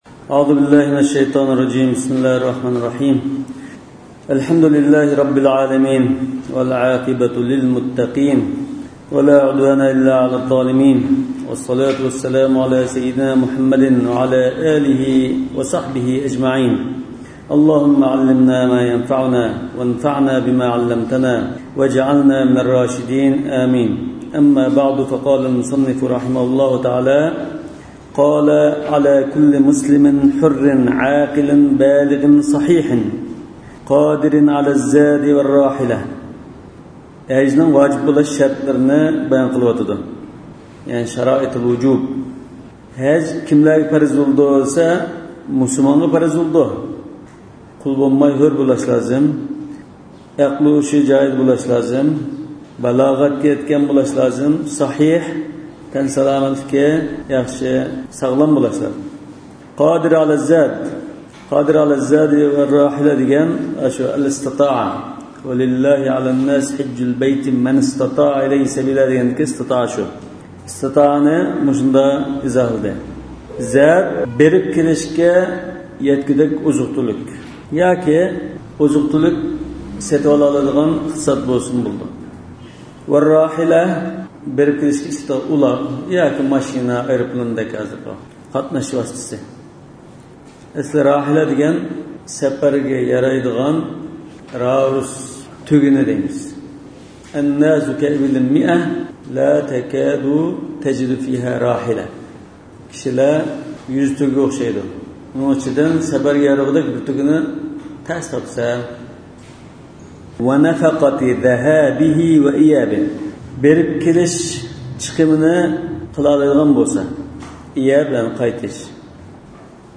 ئاۋازلىق دەرسلەر